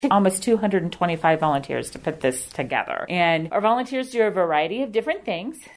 You’re hearing the sounds of the Manhattan High School orchestra, one of several volunteers to help put on the zoo’s most popular annual event.